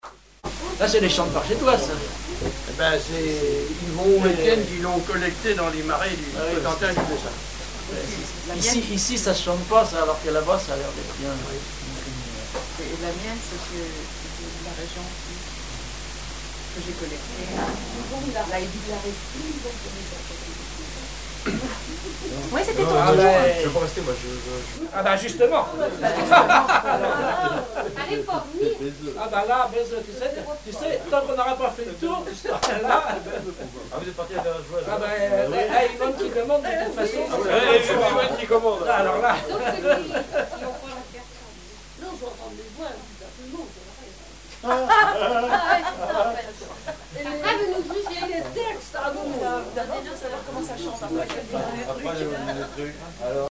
Présentation de la chanson Le petit mari
Chansons et commentaires
Catégorie Témoignage